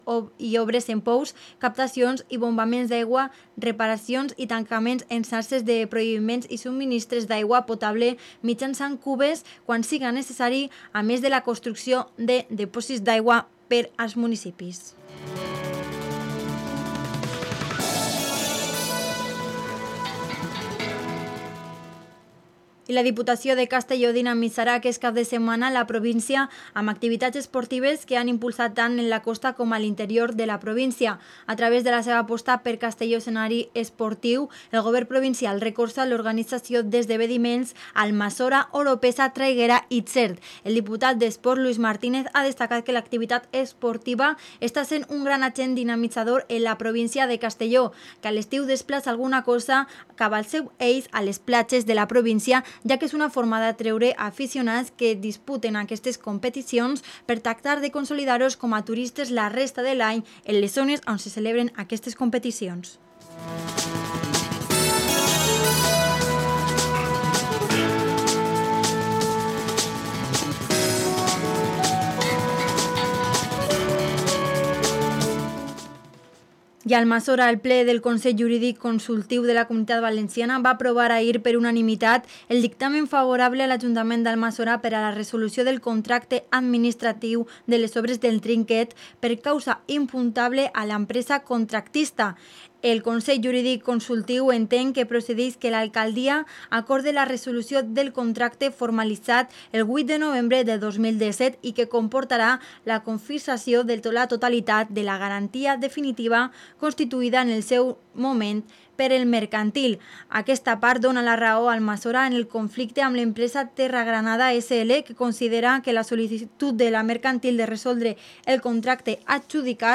Inauguramos nueva sección para descubriros rutas de senderismo por la provincia de Castellón y, en el apartado musical, escucharemos bandas sonoras de cine.